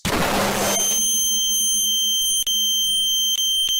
Texture-Pack/assets/minecraft/sounds/fireworks/blast1.ogg at master
blast1.ogg